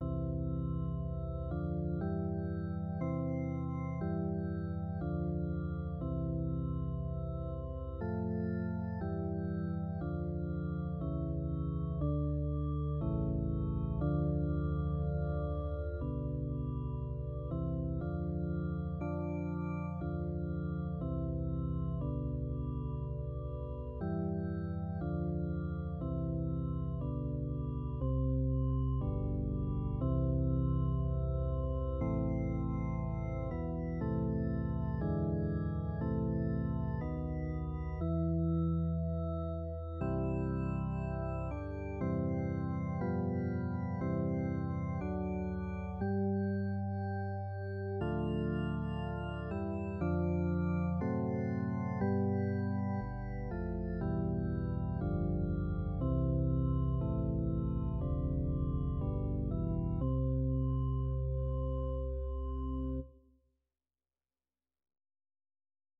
szerző: zene: Erkel Ferenc, vers: Kölcsey Ferenc
Egynemű karra